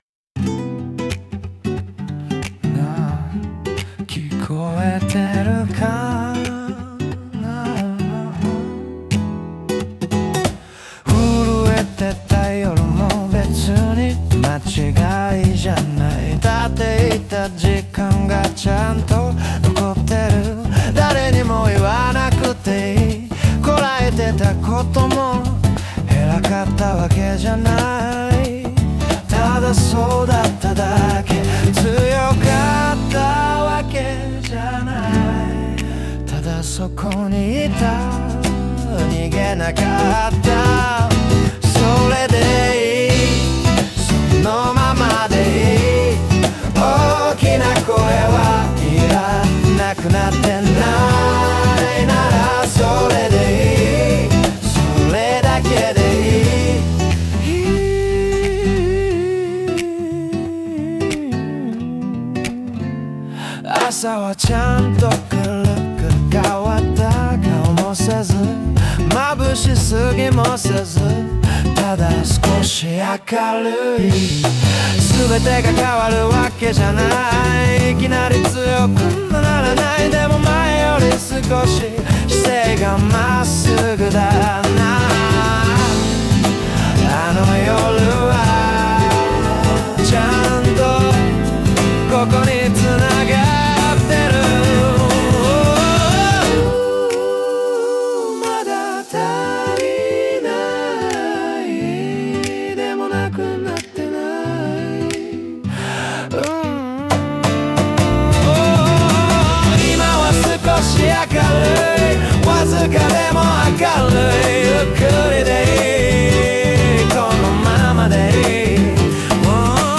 男性ボーカル
イメージ：男性ボーカル,アコースティックファンク,クール,アシッドジャズ